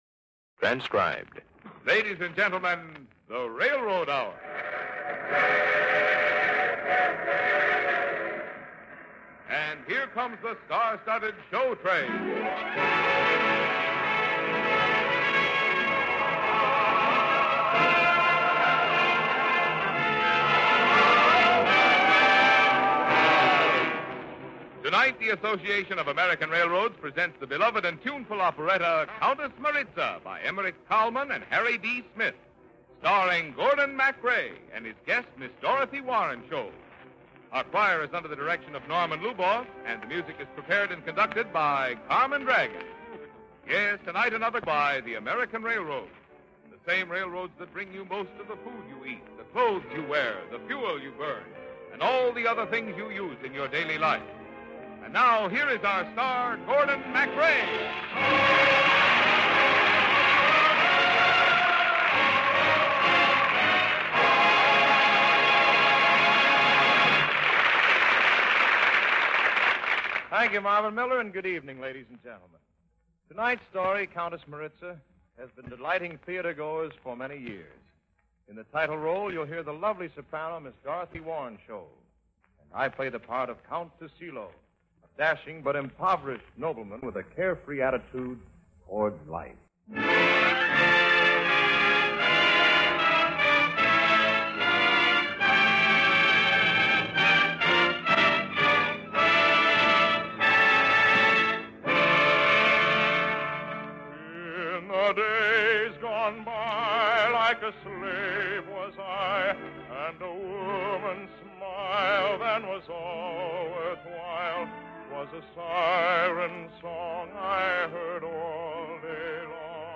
radio series
musical dramas and comedies
hosted each episode and played the leading male roles